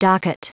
Prononciation du mot docket en anglais (fichier audio)
Prononciation du mot : docket